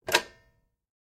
03TrubkaPodnjatie.ogg